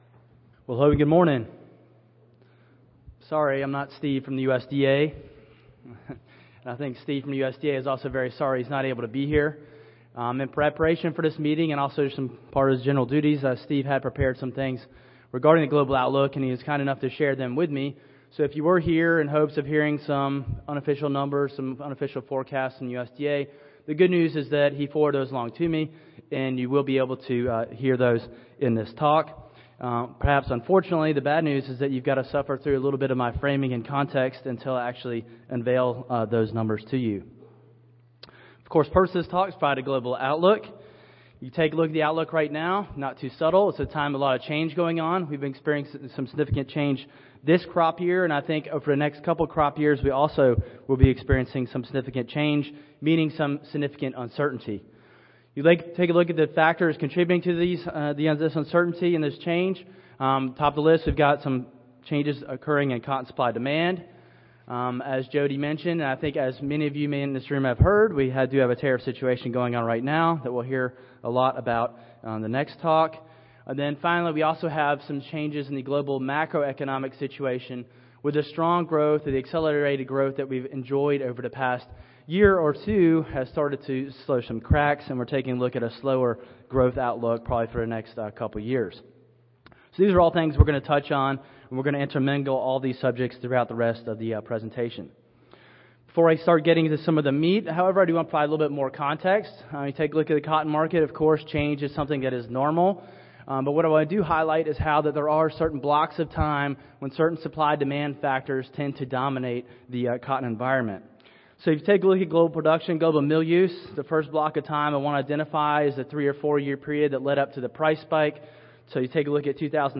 Mardi Gras Ballroom Salon D (New Orleans Marriott)
Recorded Presentation